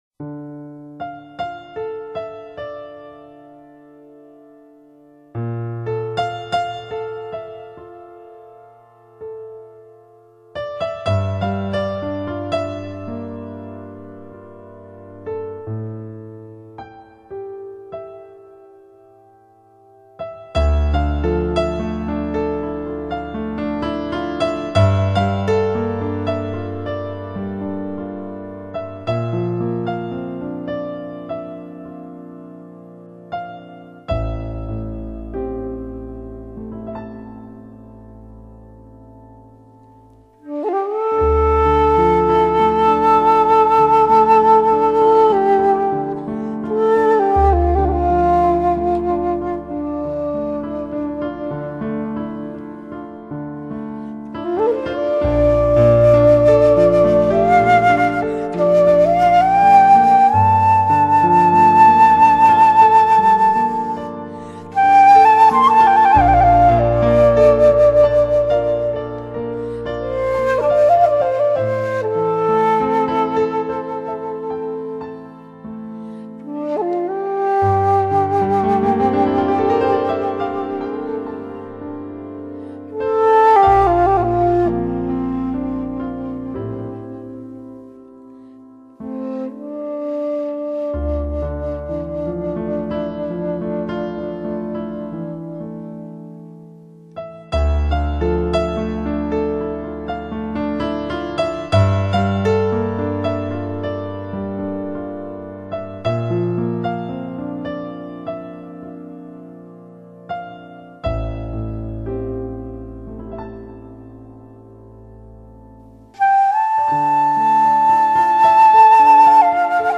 萧声遇上钢琴曲，皆溶入夜色。
在萧与钢琴声中，淡淡进入往事的回忆。